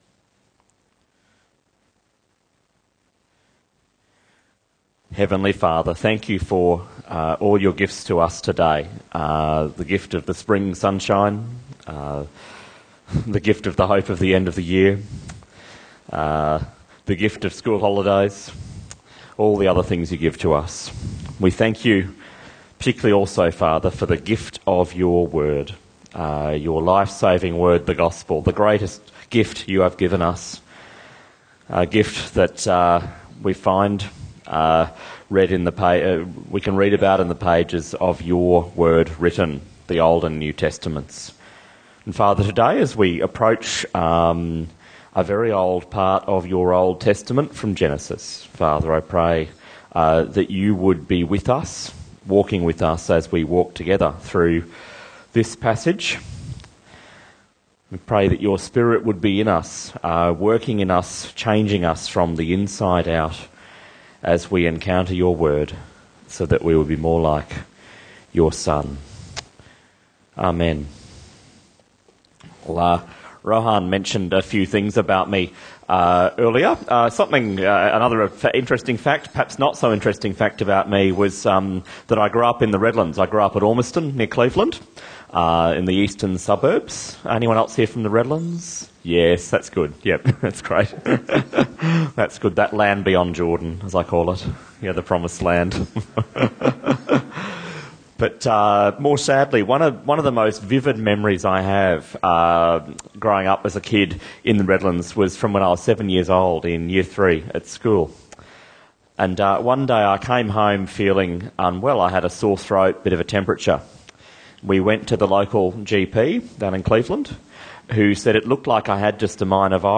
Bible Talks Bible Reading: Genesis 22:1-19